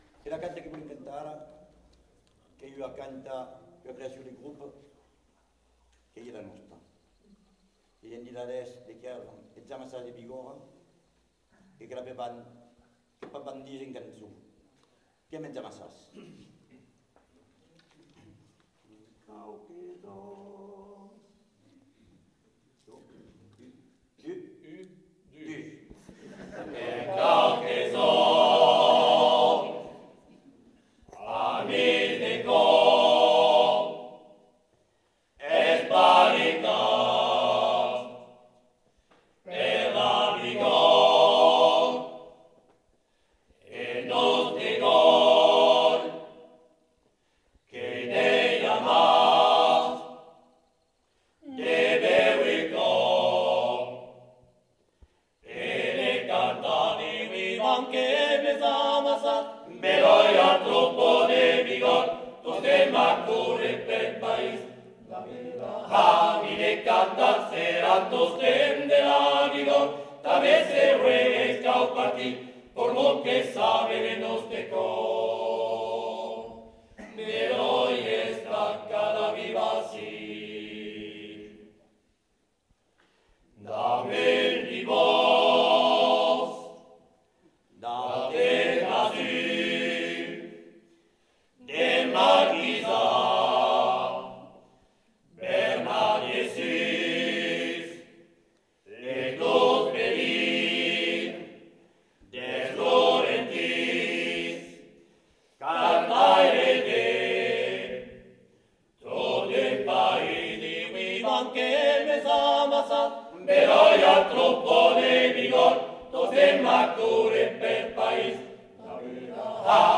Eths amassats de Bigòrra (ensemble vocal)
Aire culturelle : Bigorre
Lieu : Ayros-Arbouix
Genre : chant
Type de voix : voix d'homme
Production du son : chanté
Descripteurs : polyphonie